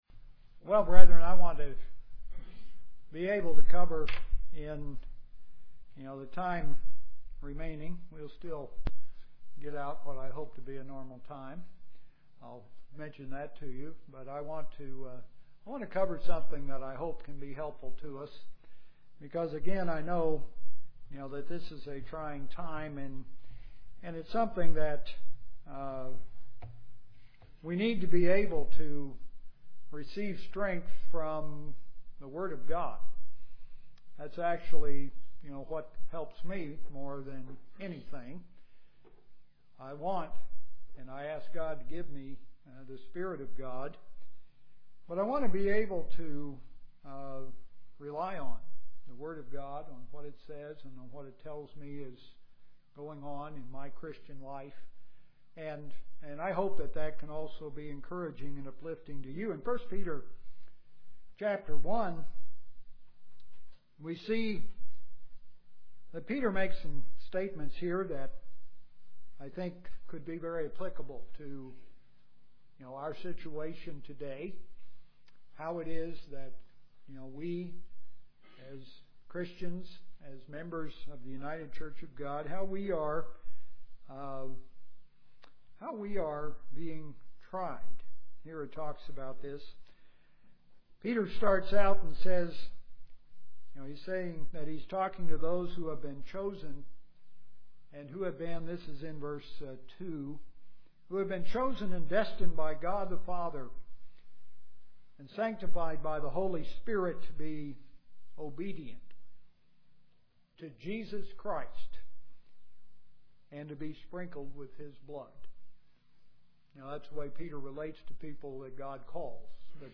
Sermon to encourage all to persevere.